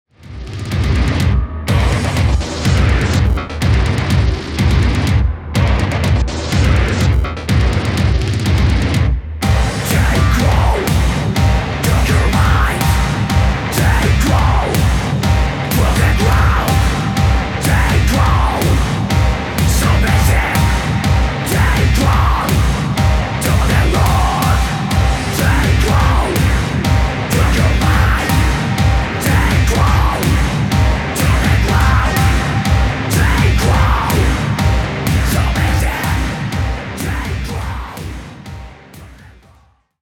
Satanischer Death Industrial aus Mexico.